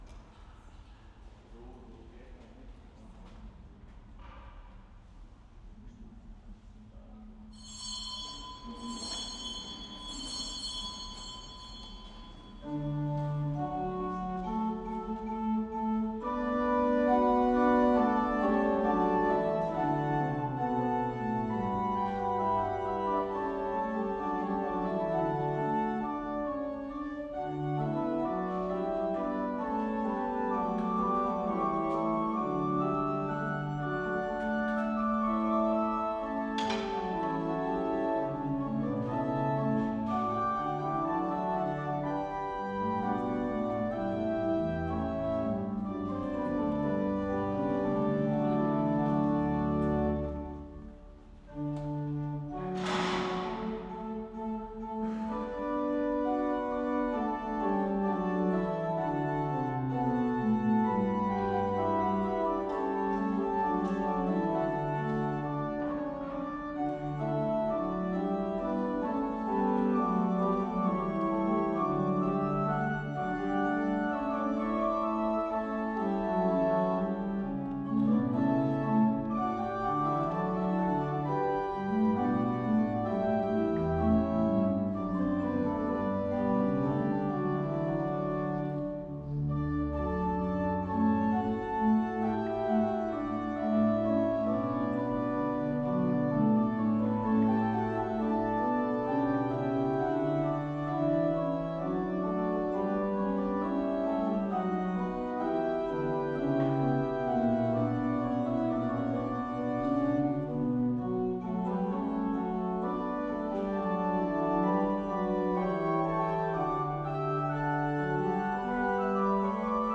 Gottesdienst vom 21. Dezember 2025 zum Nachhören - Alt-Katholische Pfarrgemeinde Baden-Baden